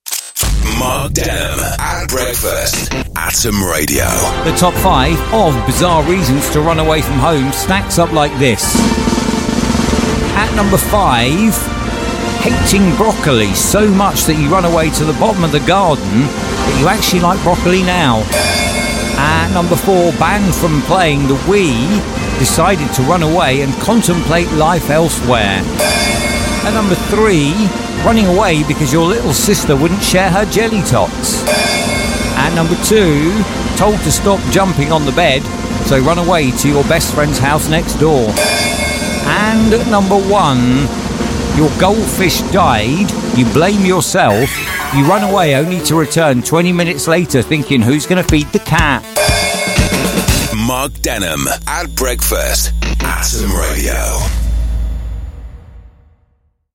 🚀 Ever run away from home for the wildest reason? From chasing a kebab to avoiding vegetables, our listeners share the most unbelievable childhood escape attempts!